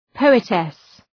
Προφορά
{‘pəʋıtıs}